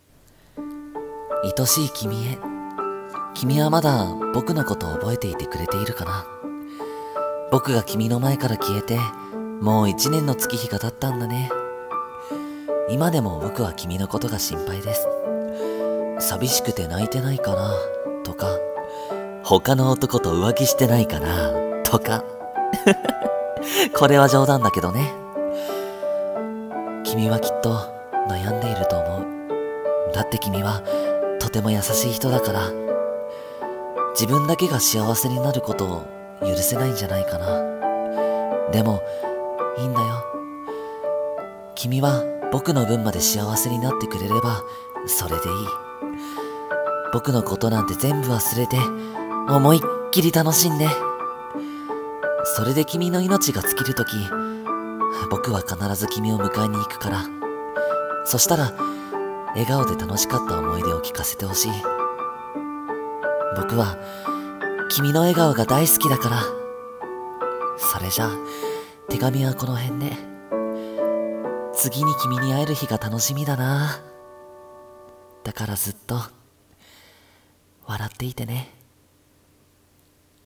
天国からの手紙【一人声劇】